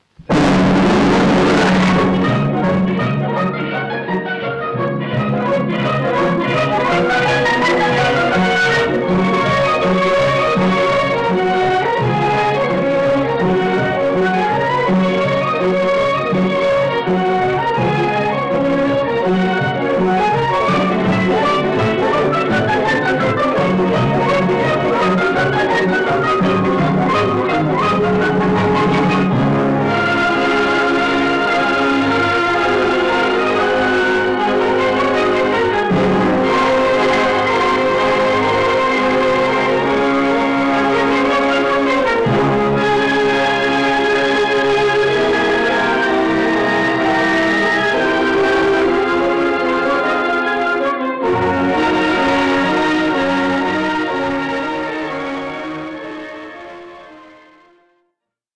Original Track Music (1.00)